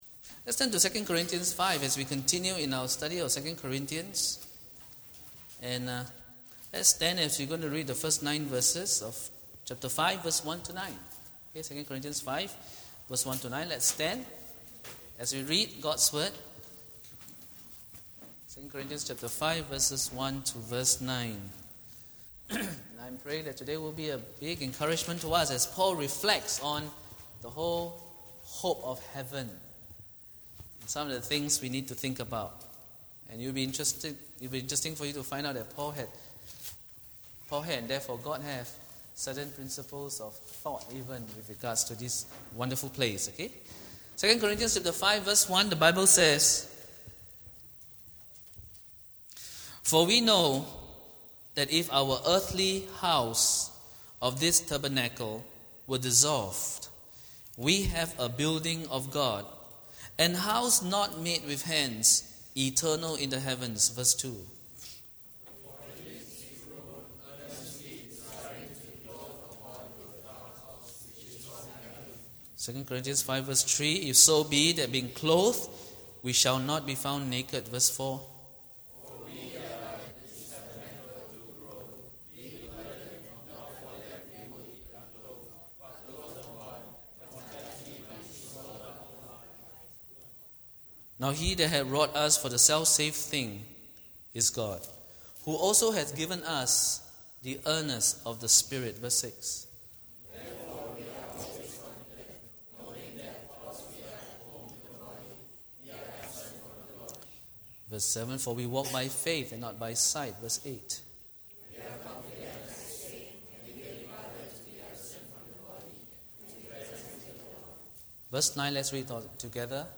Download the sermon audio here Download the notes to this sermon here